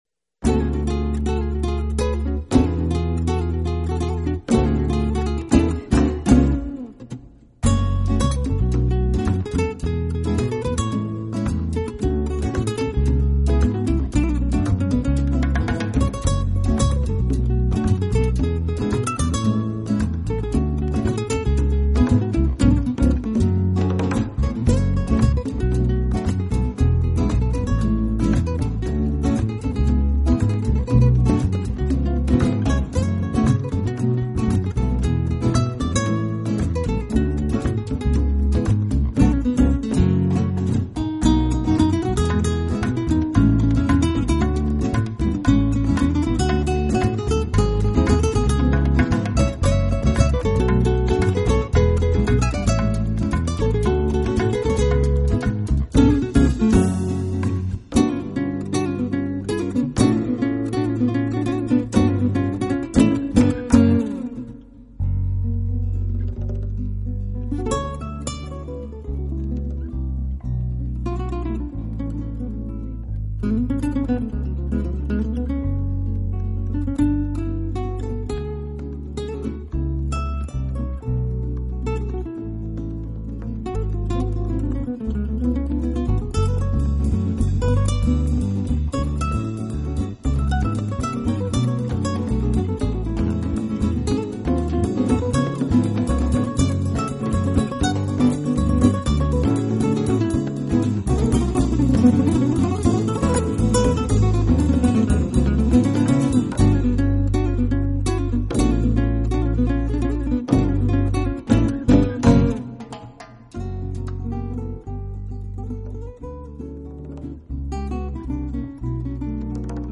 曲风基本保持了很传统的西班牙拉丁